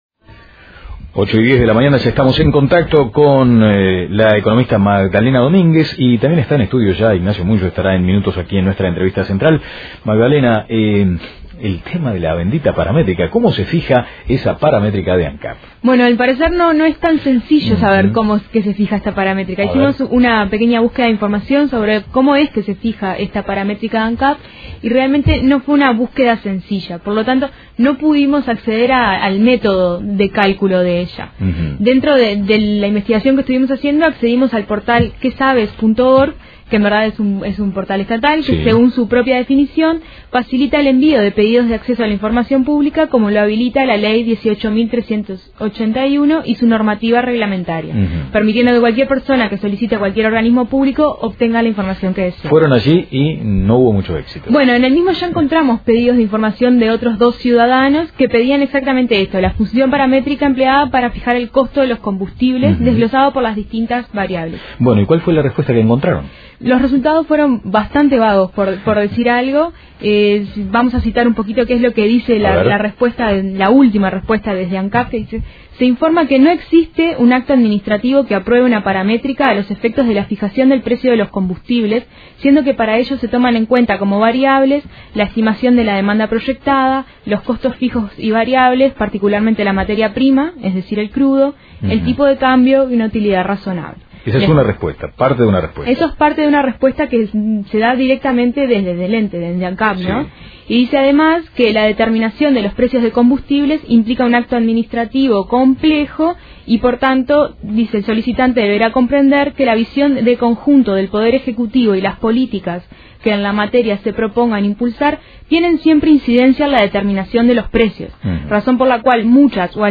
Análisis económico